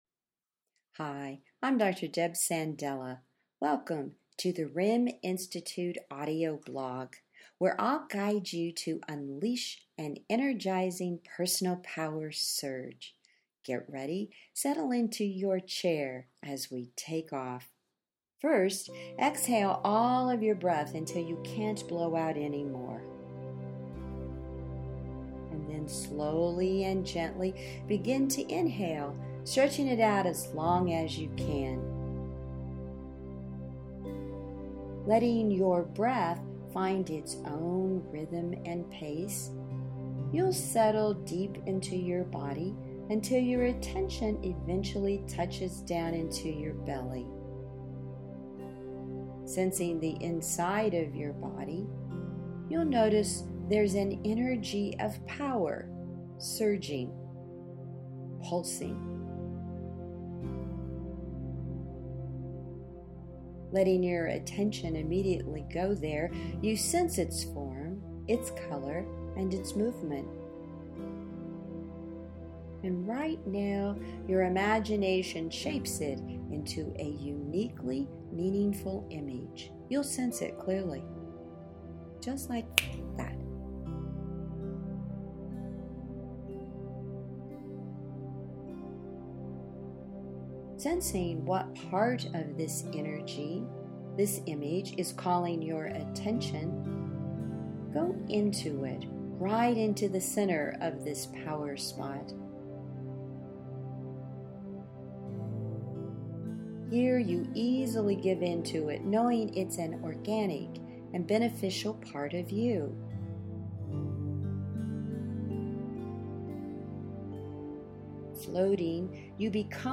Enjoy this Month’s Audio Meditation: